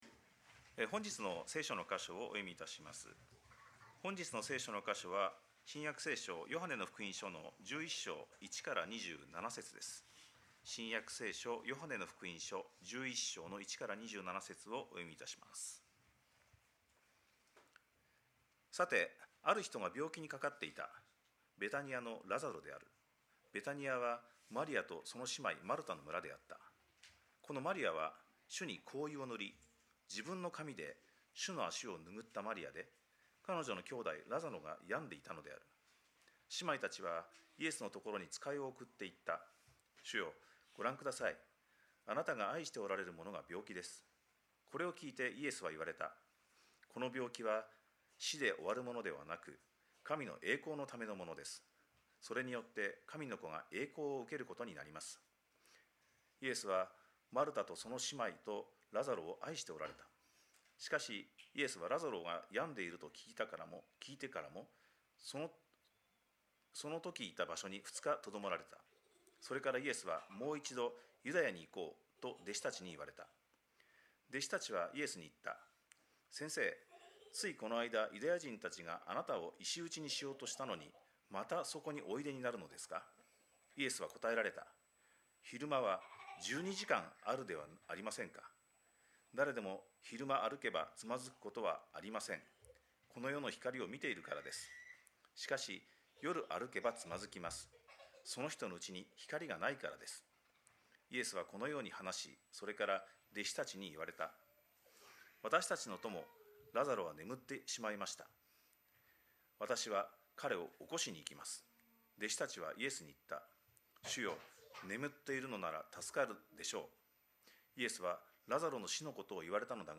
2026年4月12日礼拝 説教 「わたしを信じる者は死んでも生きるのです」 – 海浜幕張めぐみ教会 – Kaihin Makuhari Grace Church